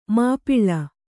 ♪ māpiḷḷa